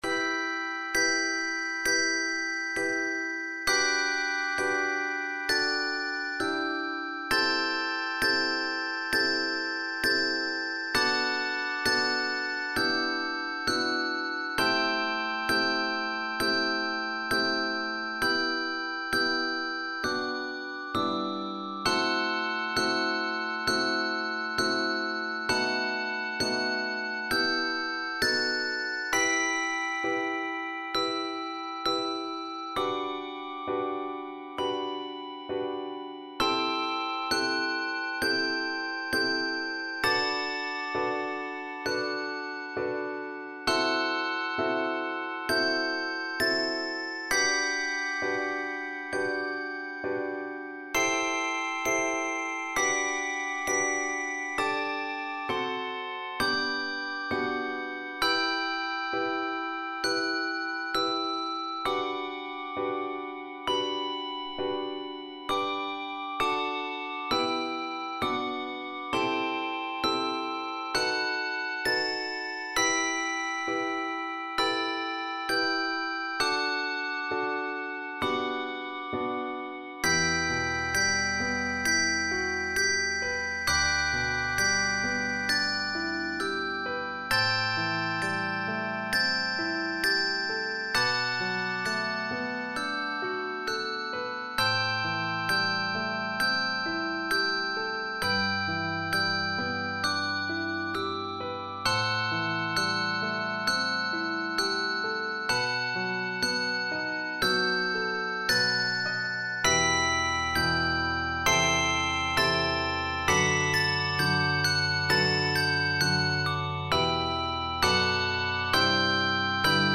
Voicing: 12 bells (